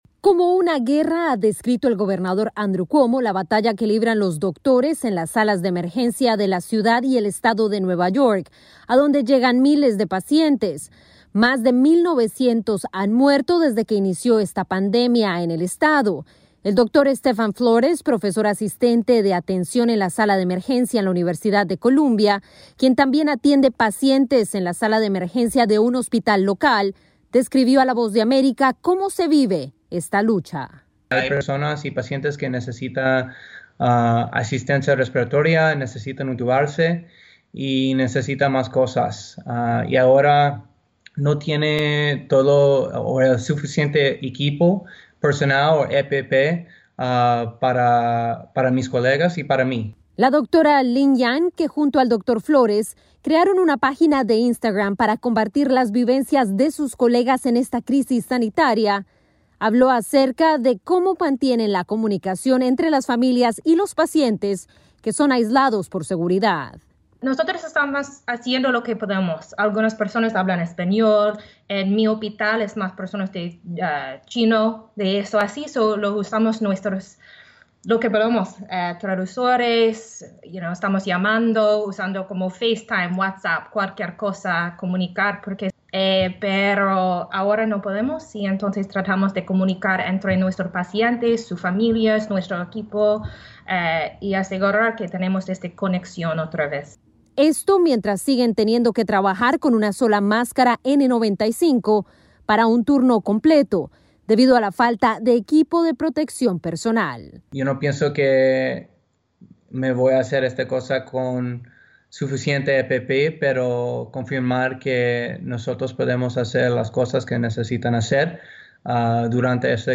AudioNoticias
Nueva York aumenta el número de víctimas fatales por el COVID 19 y el gobernador Andrew Cuomo afirma que es una lucha en la que los soldados están en las salas de emergencia. Desde la Voz de América en Nueva York